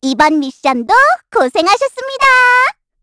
Estelle-Vox_Victory_kr.wav